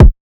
KICK 3.wav